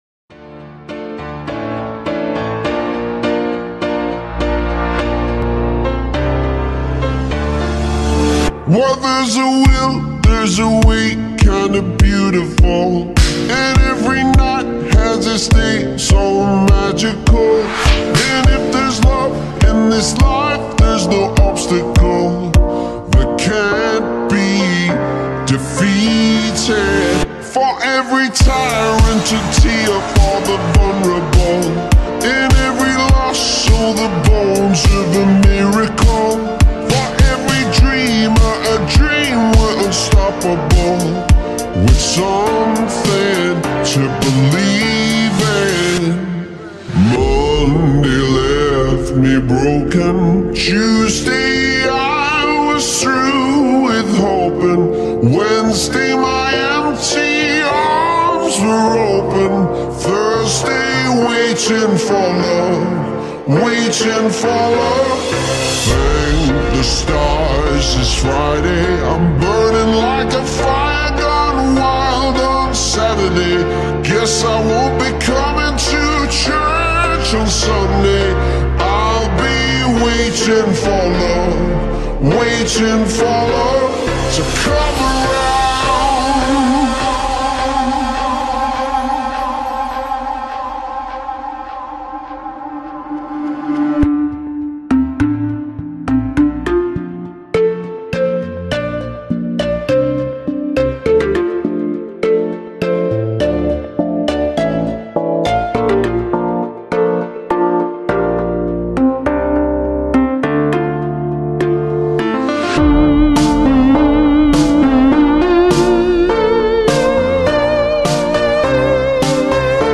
ریتمی کند شده
شاد